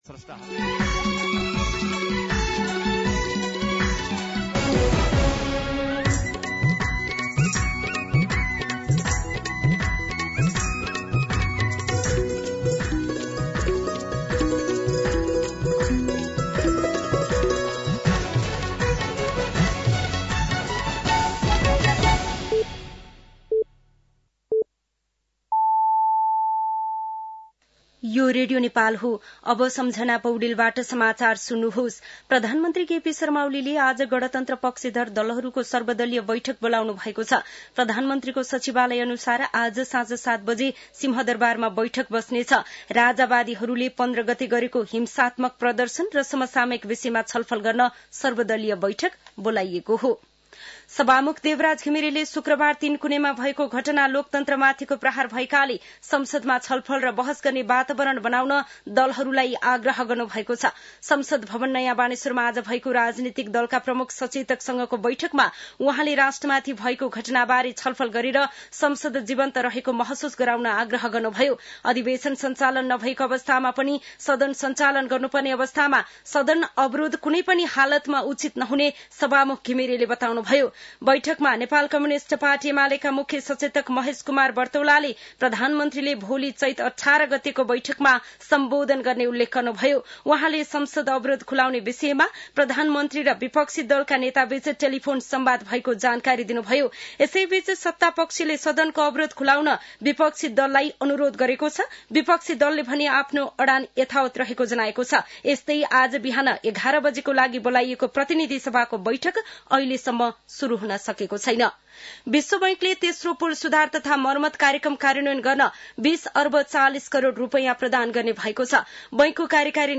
दिउँसो १ बजेको नेपाली समाचार : १७ चैत , २०८१
1pm-News-12-17.mp3